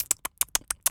mouse_eating_03.wav